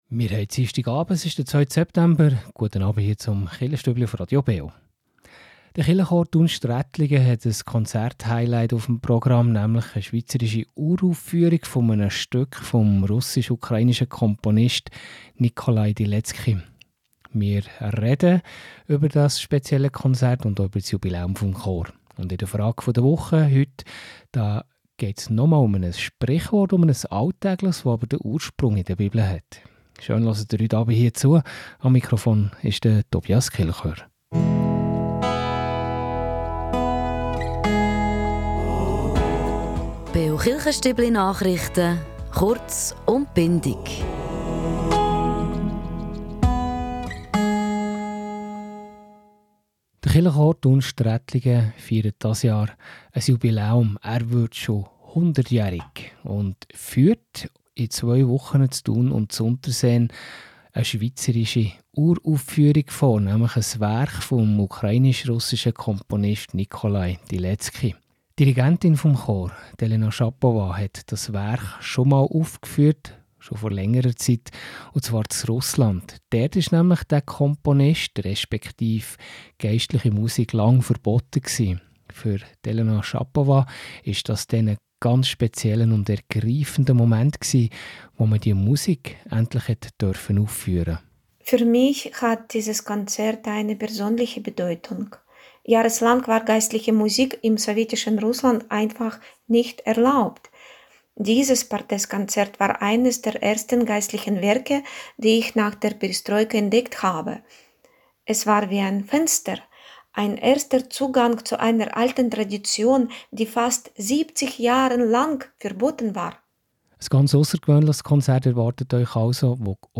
Kirchenchor Thun-Strättligen mit Uraufführung